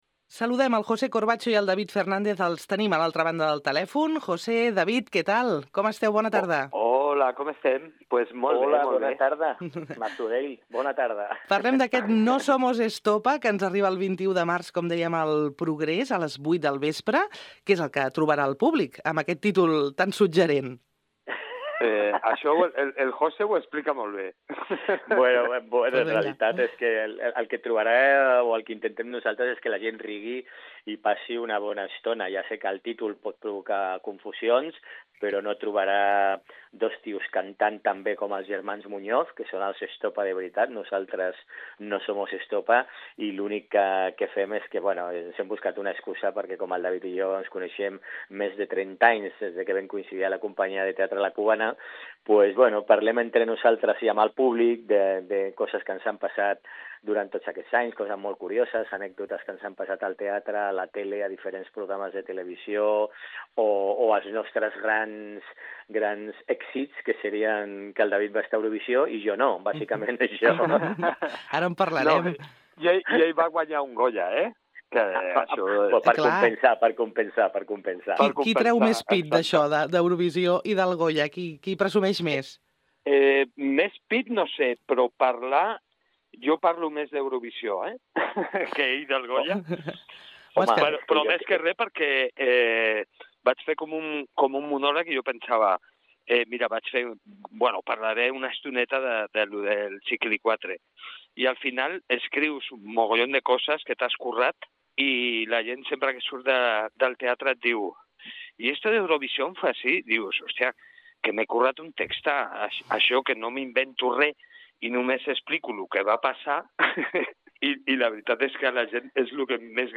Entrevista+Jose+Corbacho+i+David+Fernandez+No+Somos+Estopa.MP3